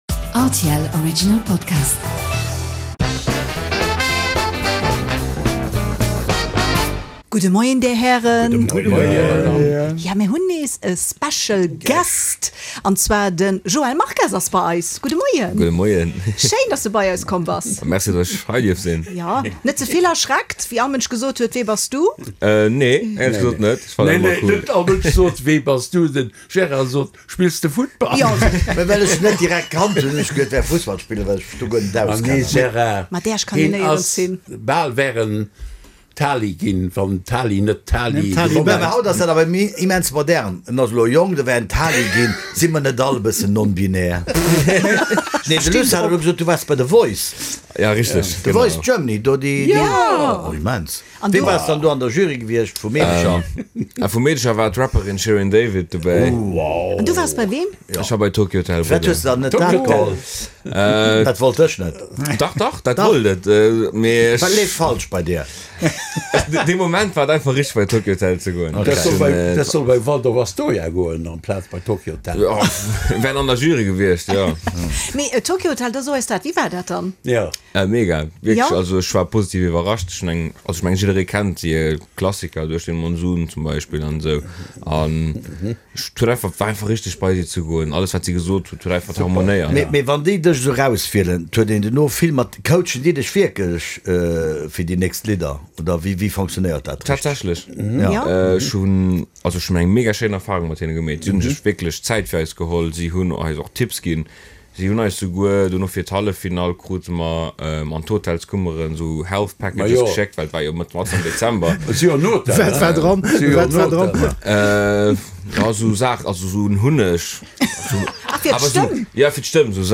Eng Table-Ronde mat Prominenz a Perséinlechkeeten déi mat vill Witz a bëssegem Humor d'Aktualitéit kommentéieren